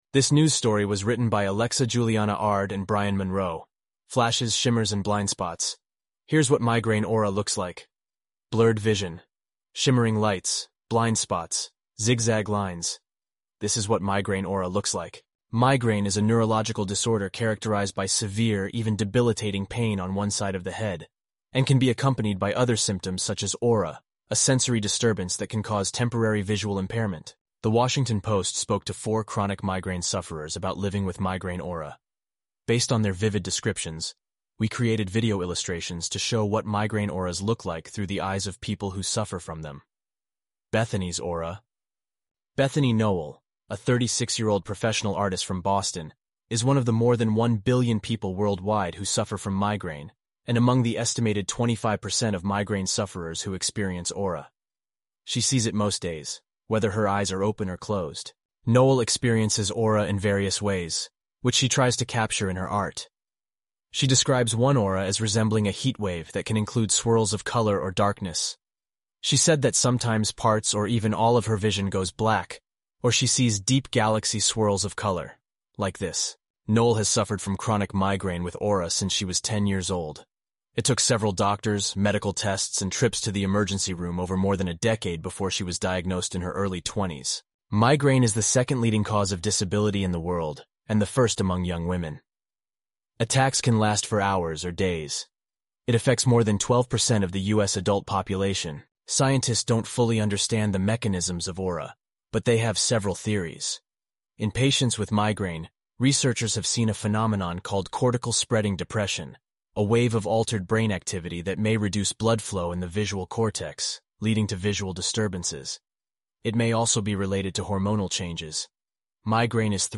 eleven-labs_en-US_Josh_standard_audio.mp3